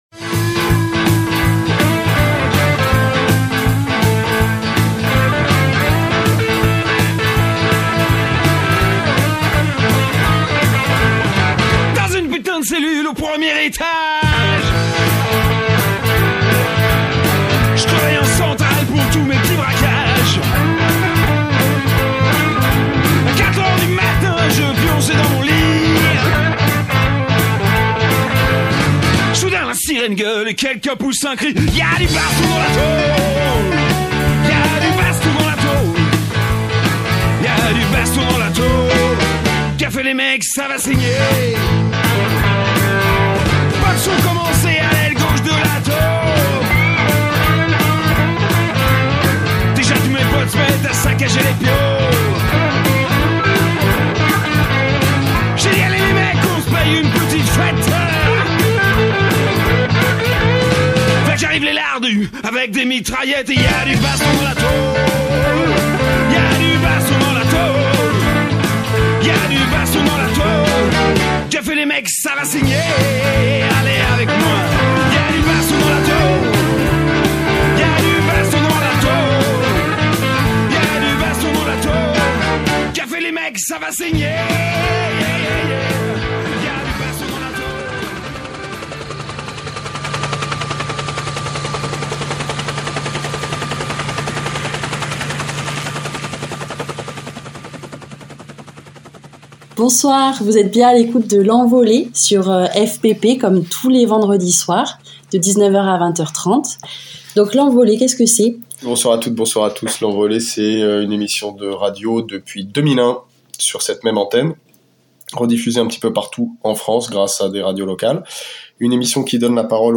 Émission de l’Envolée du vendredi 25 octobre 2024 Dans cette émission : L’Envolée est une émission radio pour en finir avec toutes les prisons.
Elle donne la parole aux prisonniers, prisonnières et leurs proches & entretient un dialogue entre l’intérieur et l’extérieur des prisons.